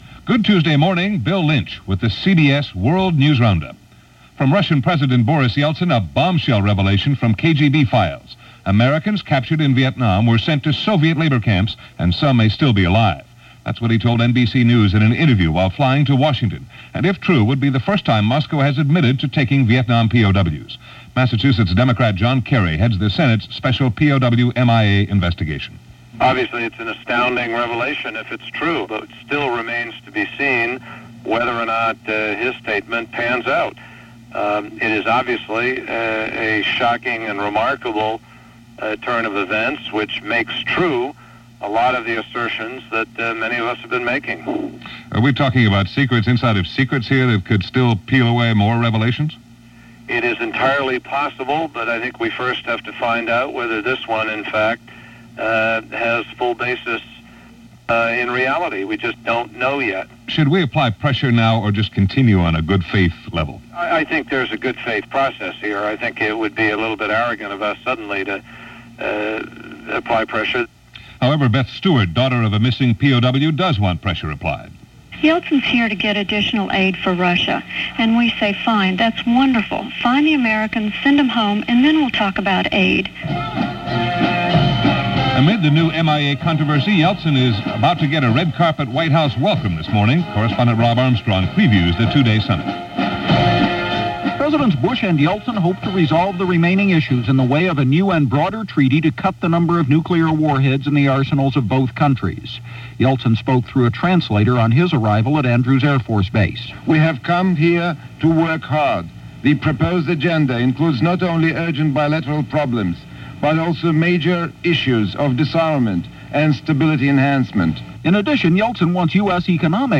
” description_text=”June 16, 1992 – CBS World News Roundup
And that’s just a little of what went on, this very busy June 16th, 1992 as presented by The CBS World News Roundup.